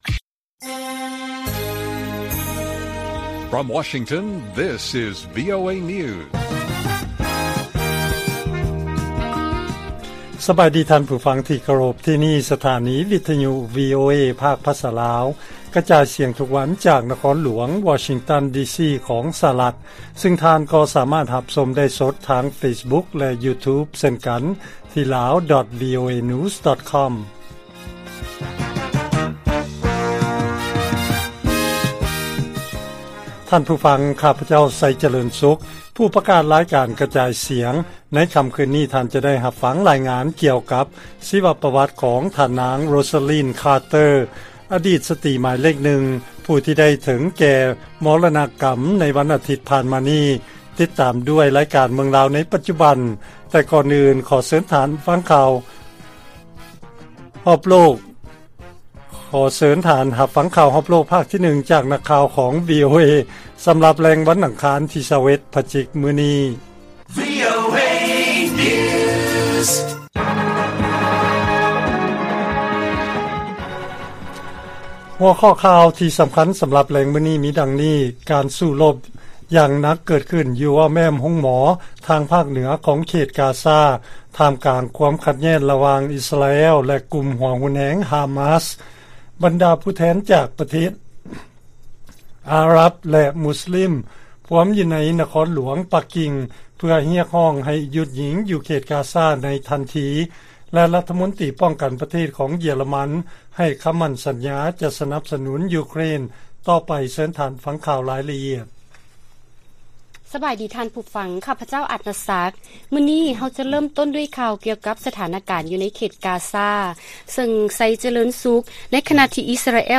ລາຍການກະຈາຍສຽງຂອງວີໂອເອລາວ: ເກີດການສູ້ລົບ ຢູ່ໃນບໍລິເວນອ້ອມແອ້ມໂຮງໝໍກາຊາ